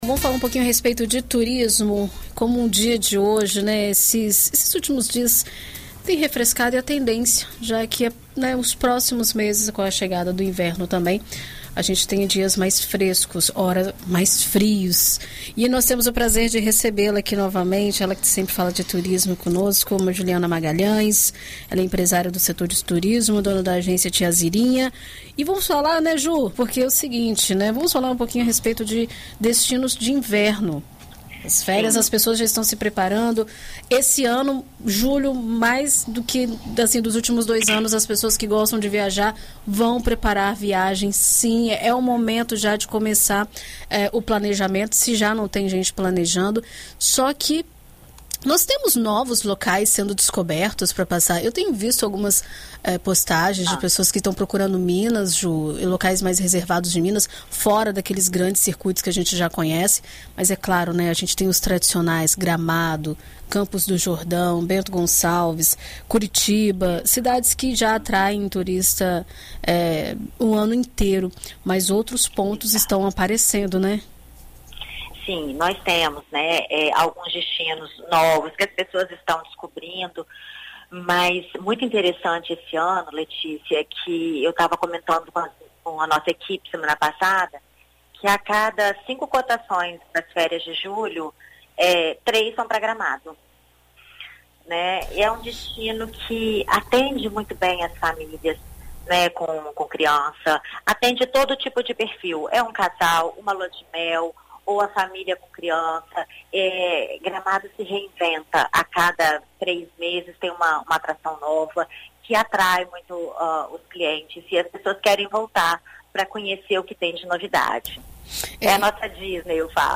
Em entrevista à BandNews FM Espírito Santo nesta segunda-feira